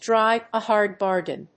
アクセントdríve a (hárd) bárgain